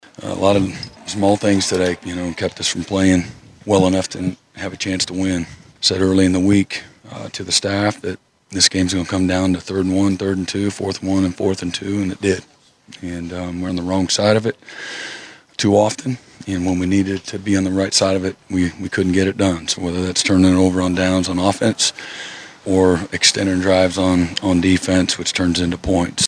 Head coach Brent Venables talked on KYFM postgame.
Venables Post Ole Miss 10-27(1).mp3